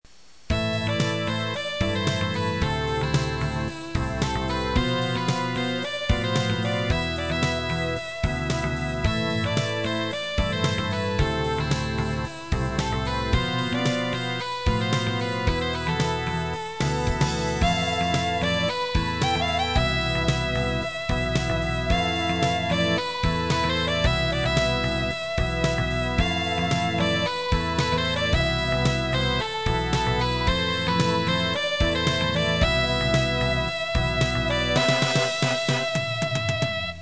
シューティングゲーム風BGMその４
制作環境：Ｘ−６８０３０ ＋ ＳＣ-８８ＶＬ